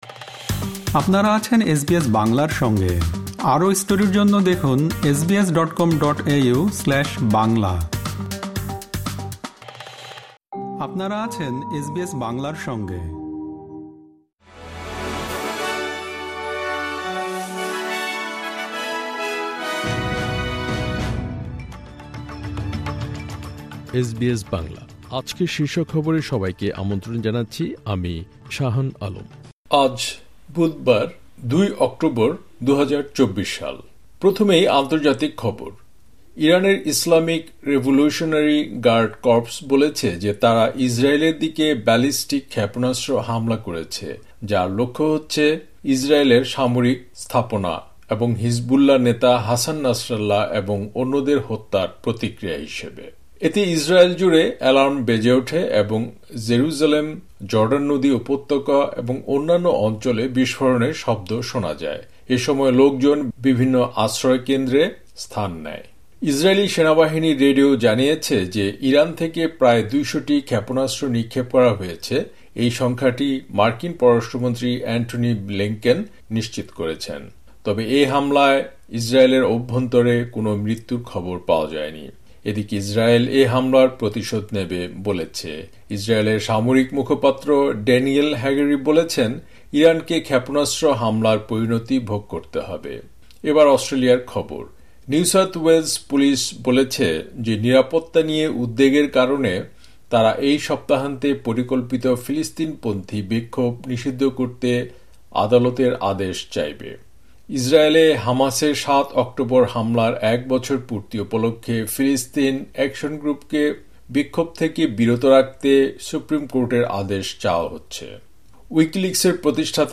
এসবিএস বাংলা শীর্ষ খবর: ২ অক্টোবর, ২০২৪